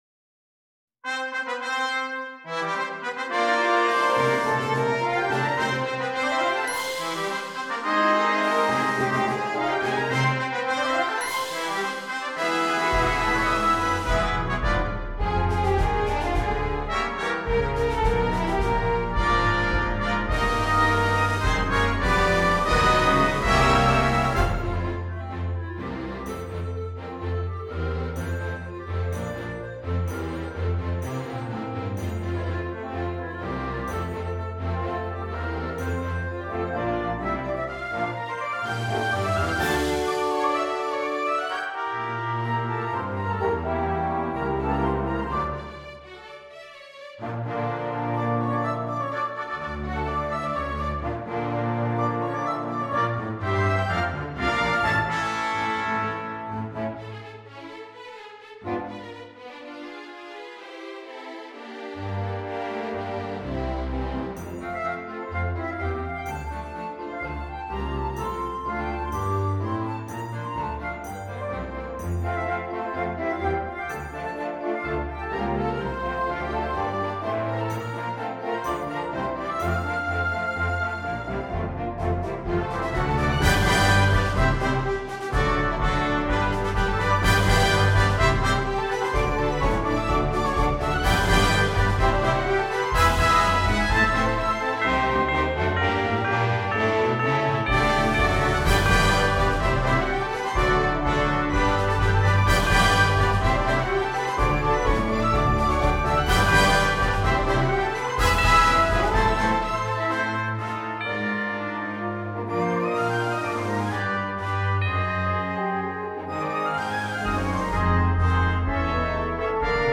Original composition for orchestra